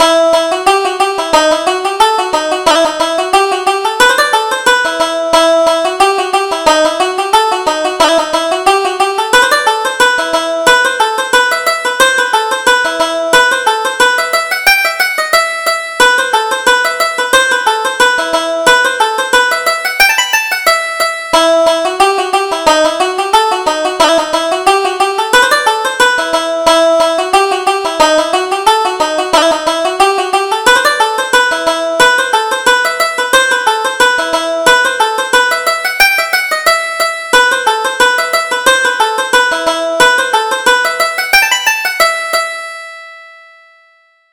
Reel: O'Reilly's Fancy